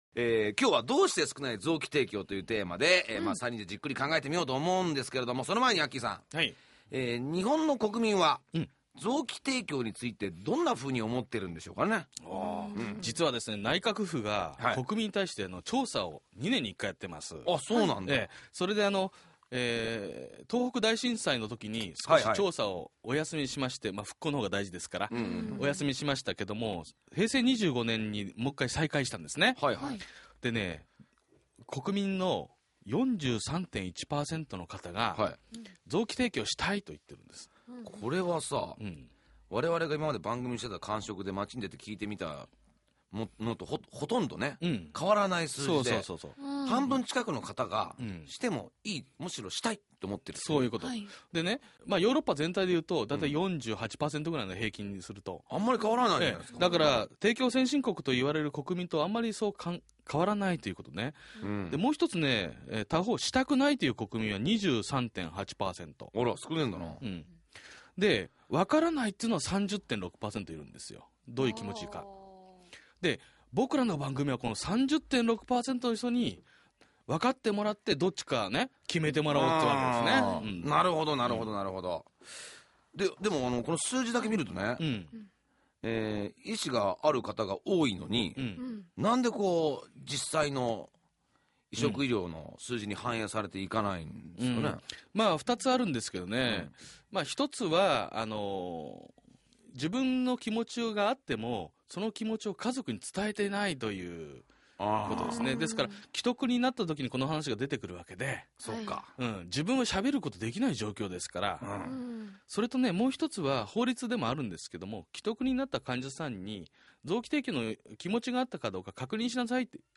※BGMやリクエスト曲、CMはカットしています。